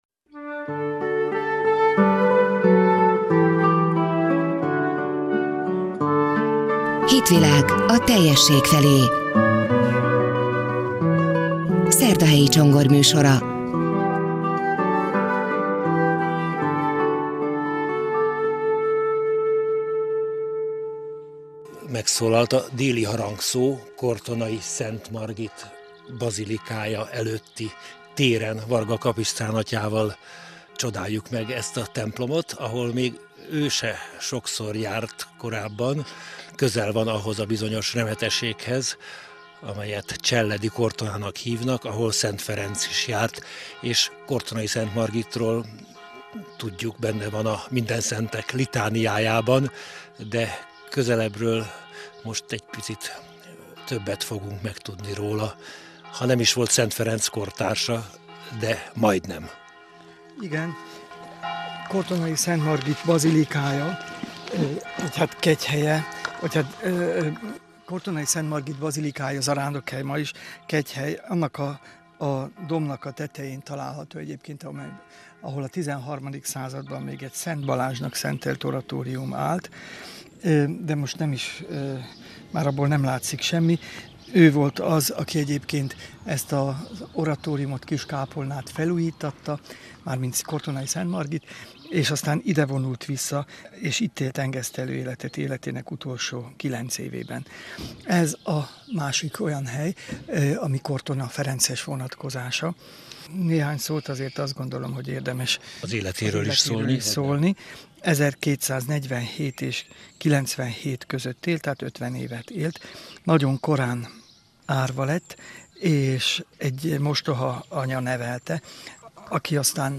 Margit nagy bűnbánó, és a szentek között tiszteljük, Illés pedig nagy építő és menedzser, akinek a ferencesek életében betöltött szerepéről ma is vitatkoznak a rendtörténészek. A Lánchíd Rádió augusztus 13-án elhangzott műsora itt meghallgatható.